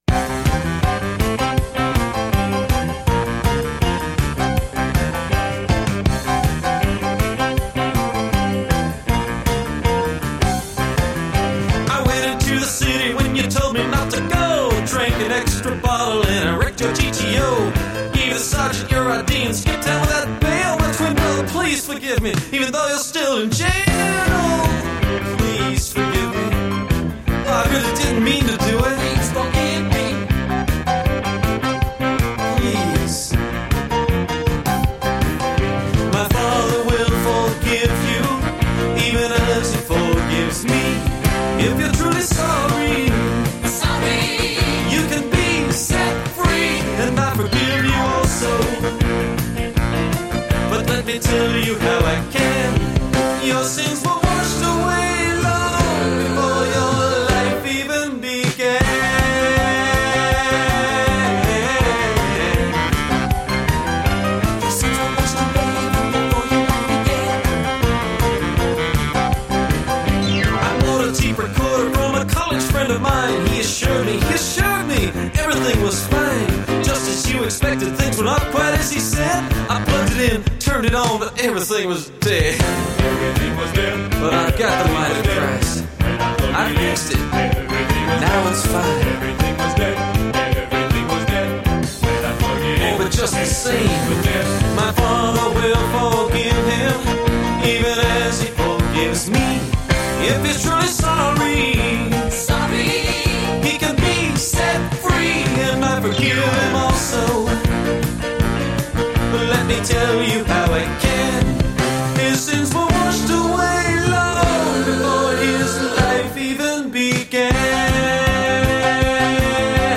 professionally recorded in 1982
Piano
Electric & acoustic guitars
Bass guitar
Percussion
Trumpet
English horn, saxophone and clarinet
Synthesizer
Background vocals